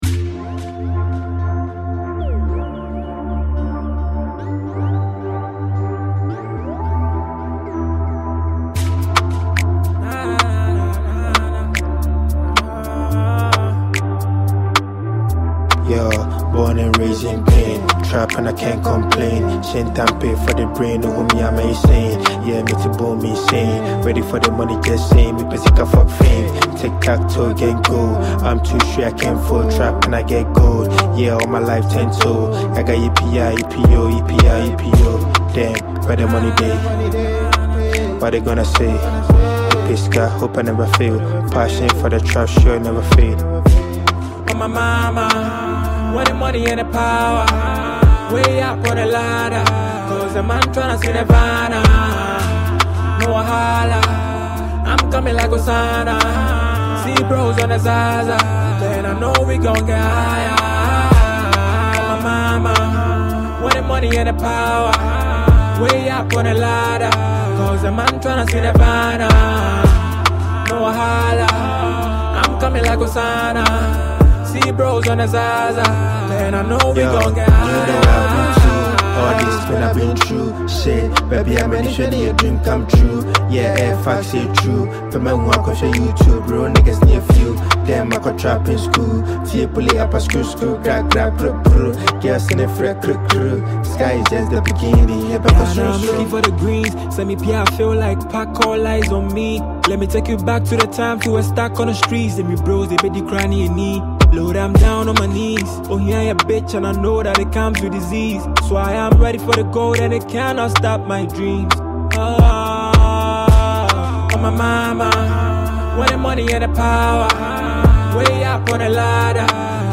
drill
bold, energetic